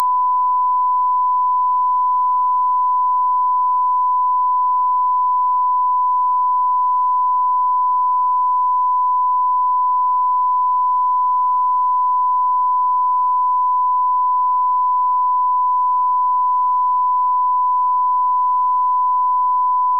Kalibrera vid behov genom att ansluta 1mW 1kHz testsignal (0dBm) till ljudkortets mirofoningång.
-12dBFS, 1kHz, 20s
testton-12dBFS-1kHz-20s.wav